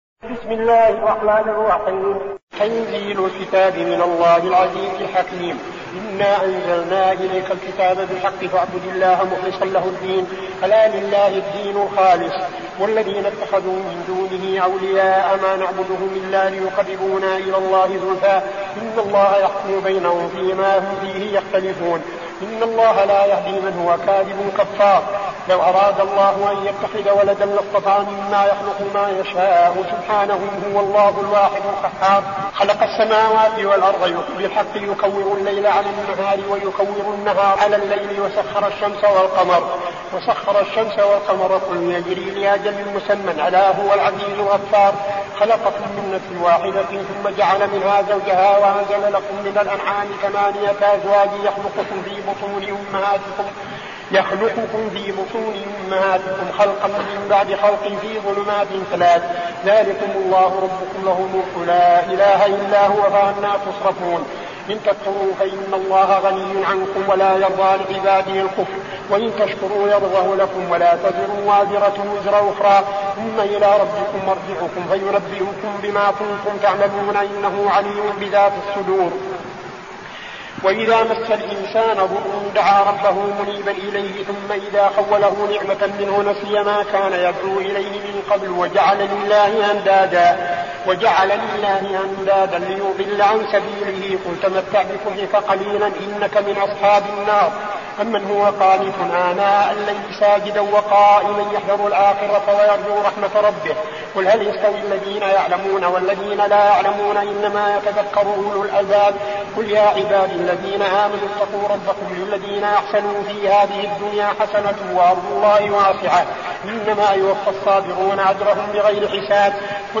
المكان: المسجد النبوي الشيخ: فضيلة الشيخ عبدالعزيز بن صالح فضيلة الشيخ عبدالعزيز بن صالح الزمر